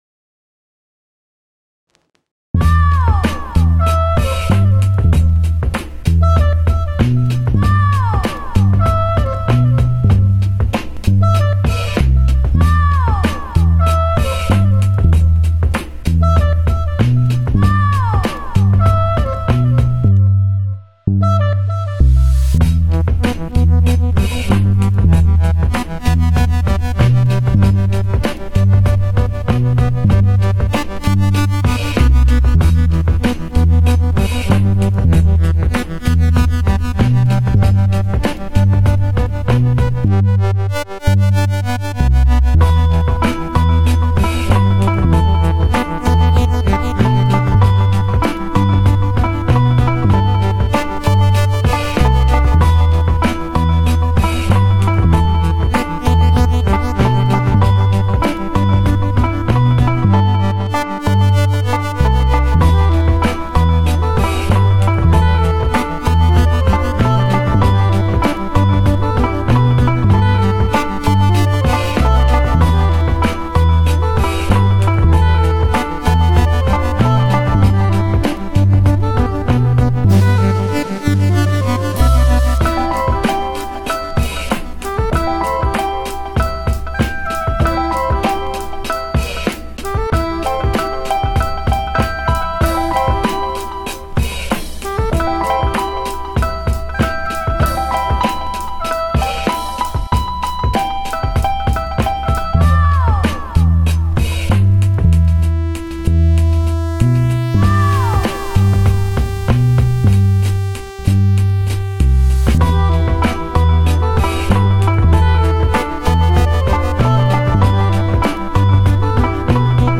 Mutta eikös triphop mennyt pois muodista jo suunnilleen kymmenen vuotta sitten?
Rahisevia sampleja ja kirkkaampia elementtejä viljellään sopivassa suhteessa. Itse jäin kaipaamaan komppeihin hieman enemmän napakkuutta, sillä nyt biisien groove tahtoo jäädä taka-alalle.